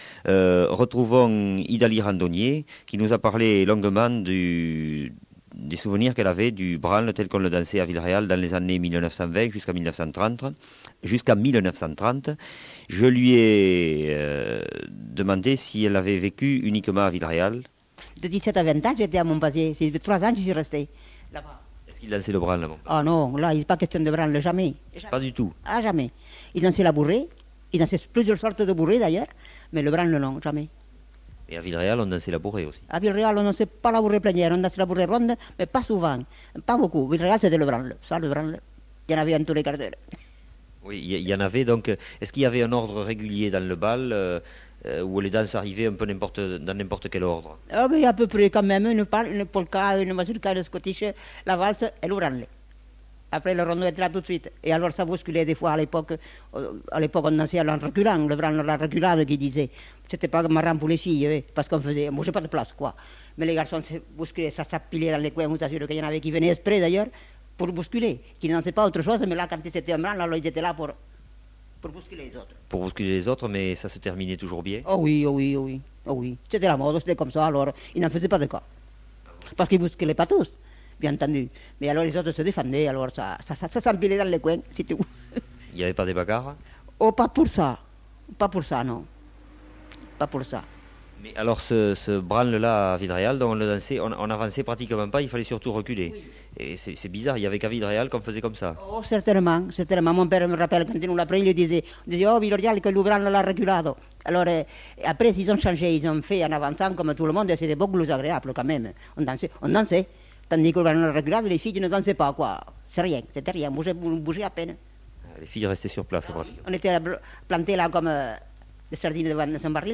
Lieu : Villeréal
Genre : témoignage thématique